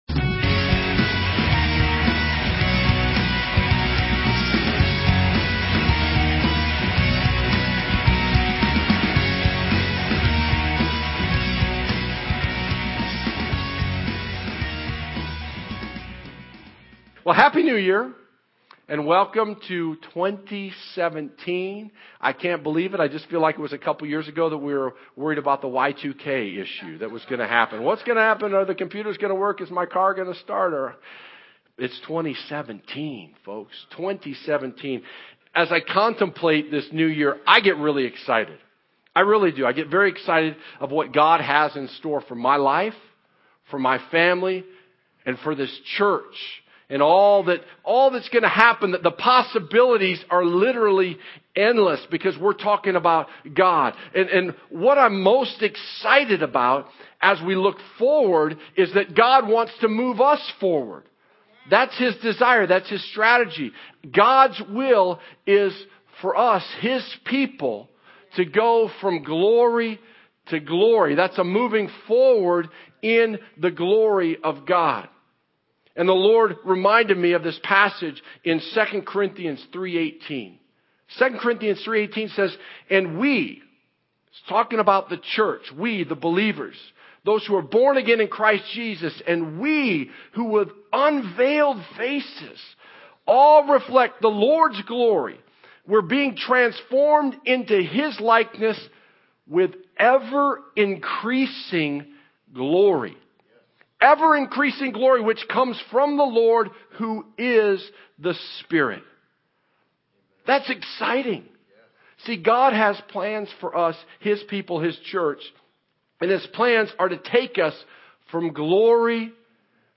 Sermon Archive (2016-2022) - Evident Life Church | Gospel-Centered, Spirit Filled Church in Gilbert, AZ